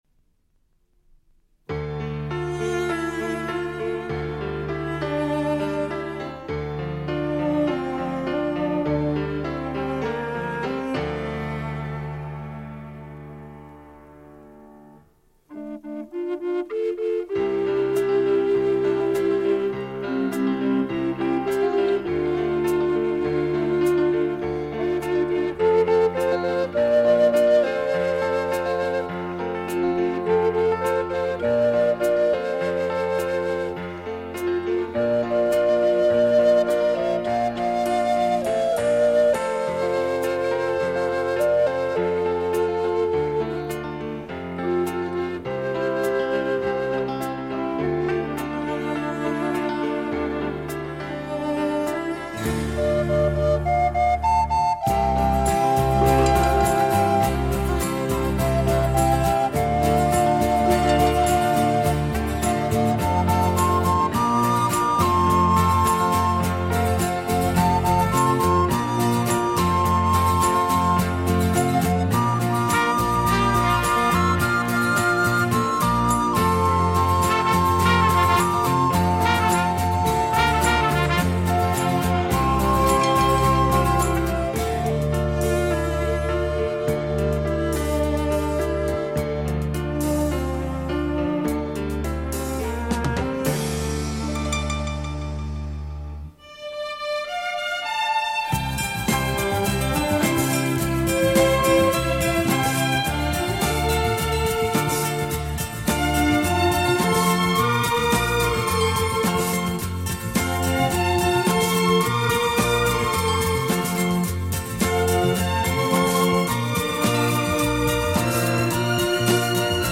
Genre: Easy Listening, Instrumental